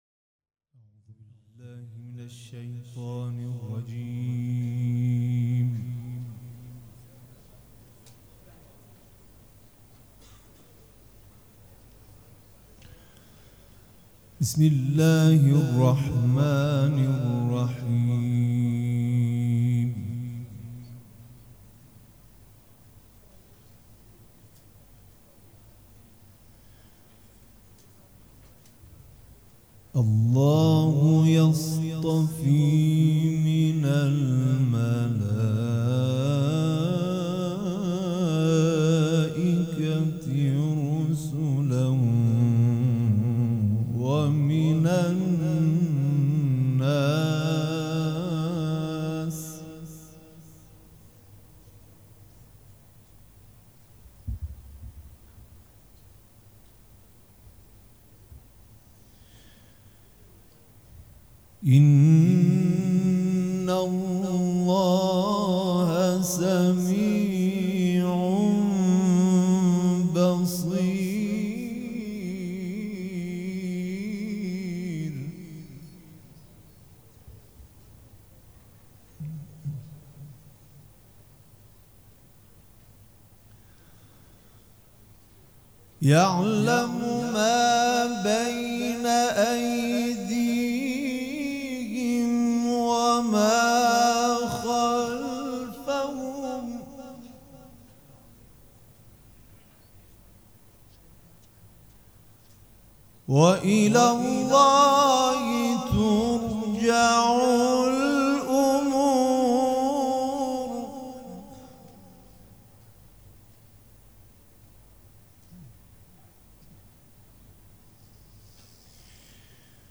جمعه 16 شهریور 1397 هیئت ریحانه الحسین سلام الله علیها
قرائت قرآن